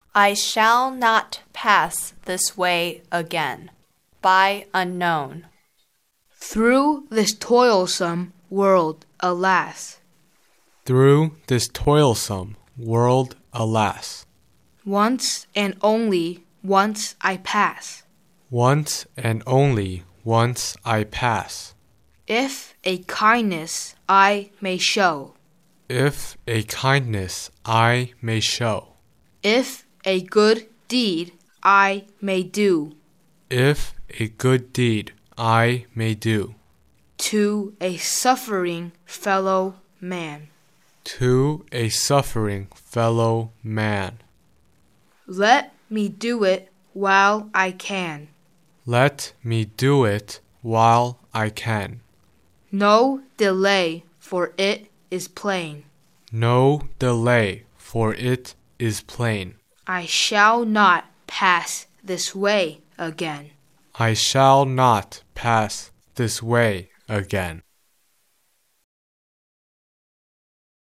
English beautiful reciting poems http